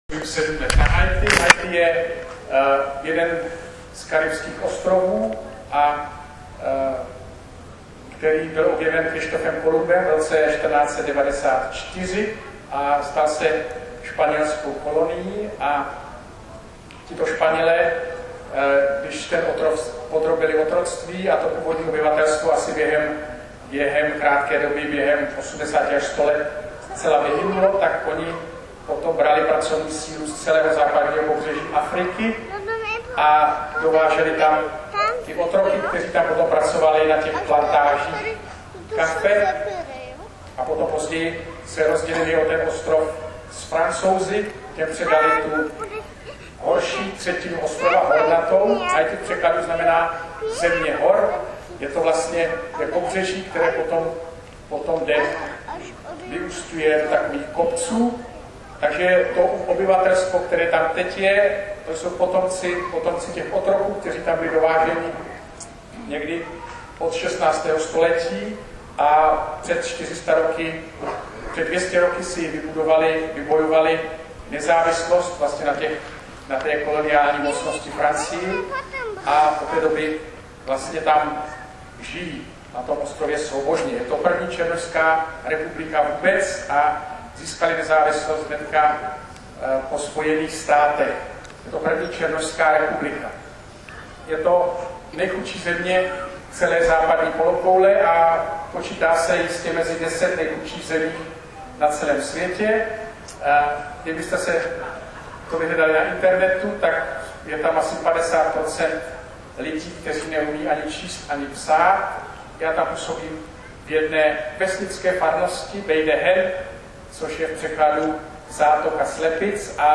Záznam je prosím hrubý a nesestříhaný, možná ho zkrátím, protože v prvních minutách byl záznam rušen povídáním jedné dívky, která byla opodál diktafonu...
Je to zajímavé povídání, kterému naslouchalo dost lidí...